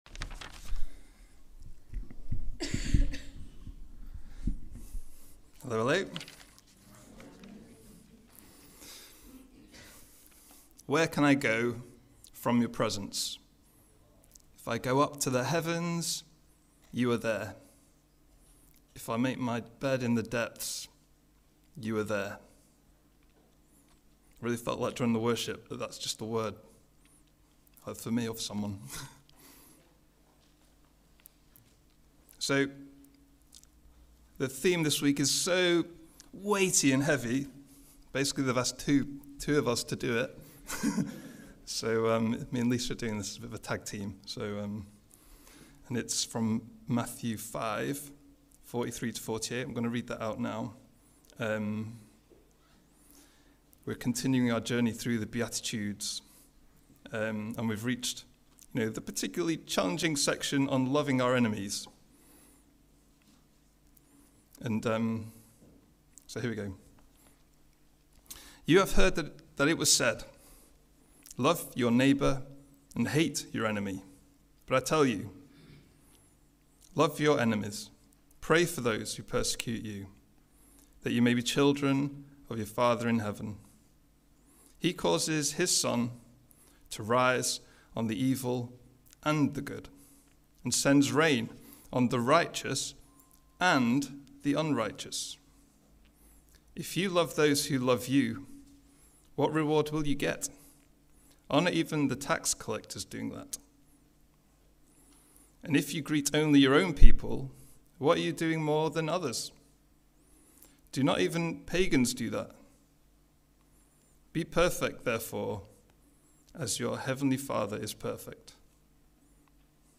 A talk from the series "The Sermon on the Mount."